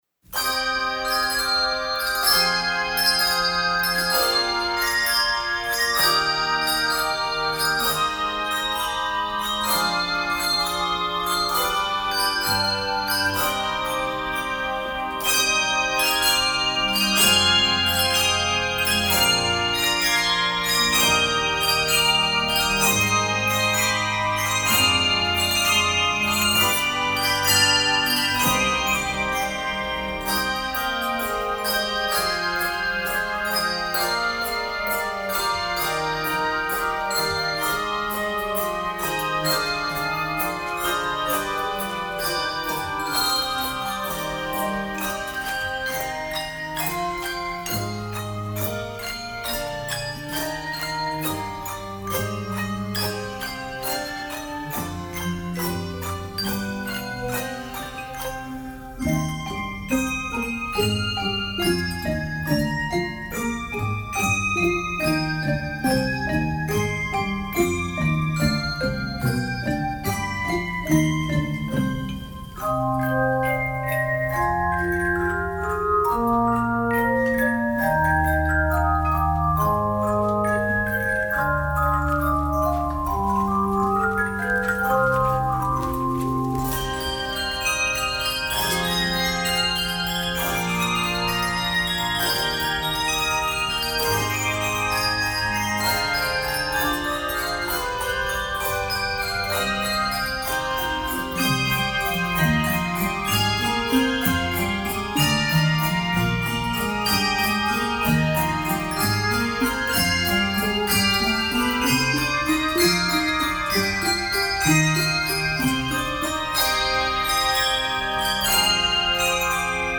Voicing: Handbells 5-7 Octave